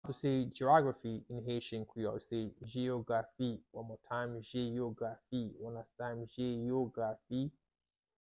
How to say "Geography" in Haitian Creole - "Jeyografi" pronunciation by a native Haitian Creole tutor
“Jeyografi” Pronunciation in Haitian Creole by a native Haitian can be heard in the audio here or in the video below:
How-to-say-Geography-in-Haitian-Creole-Jeyografi-pronunciation-by-a-native-Haitian-Creole-tutor.mp3